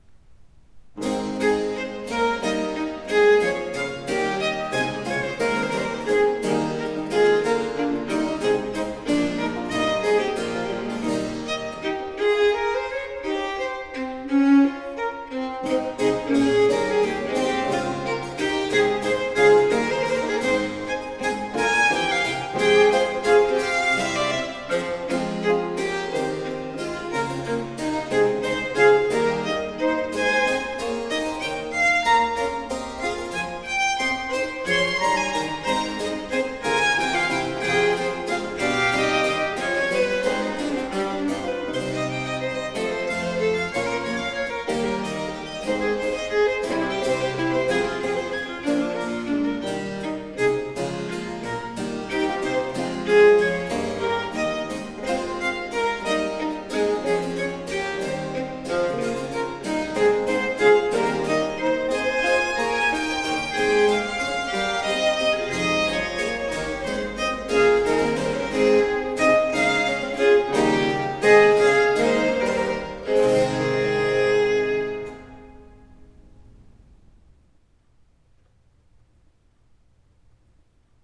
OLED SIIN ▶ muusika ▶ Klassika